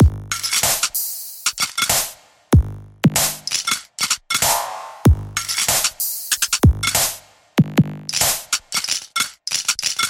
标签： 95 bpm Electronic Loops Drum Loops 1.70 MB wav Key : Unknown
声道立体声